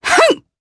Demia-Vox_Attack2_jp_b.wav